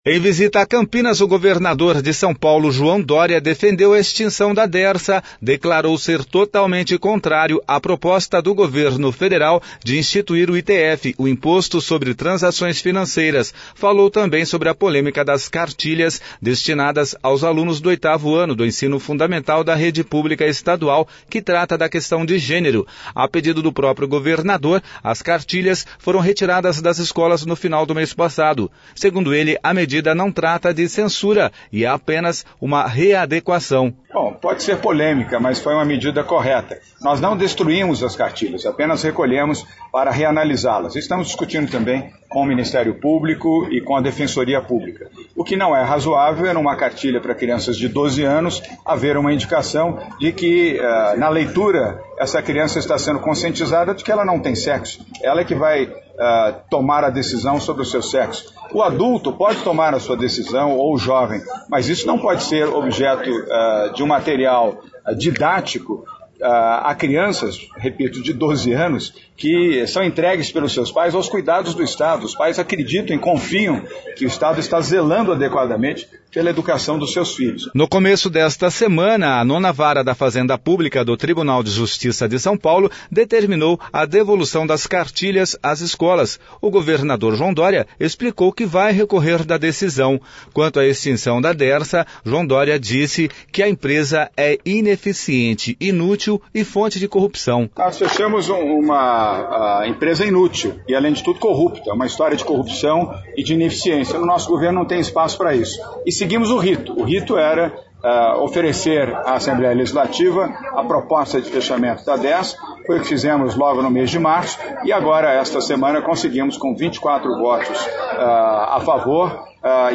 João Dória esteve nesta, quinta-feira,  em Campinas participando da solenidade de entrega de equipamentos agrícolas para o programa Cidadania no Campo, que  vai  beneficiar 20  e mais de 800 pequenos agricultores.